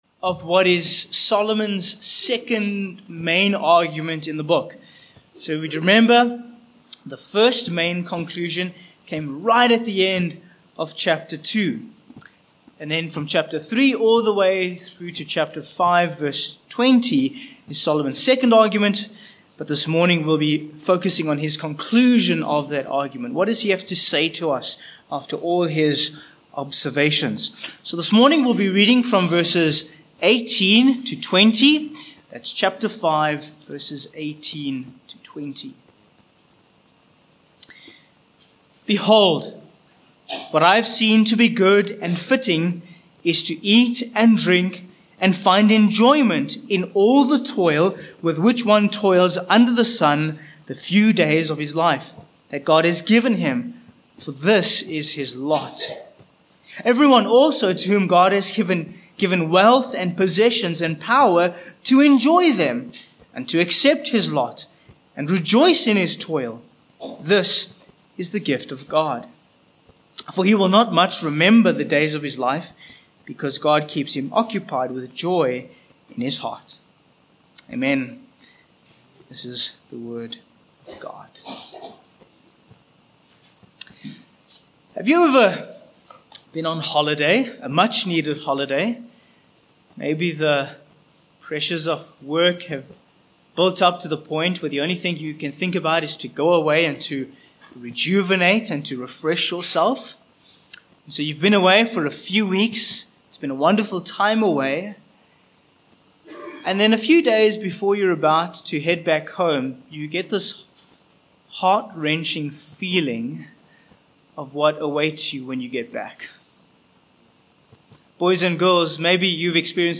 Sermon points 1. These are the Days of Our Lives v18 2. Beauty in the Midst of Brokenness v19 3. Passing the Test v20 4. Solomon in All His Glory
Ecclesiastes 5:18-20 Service Type: Morning Passage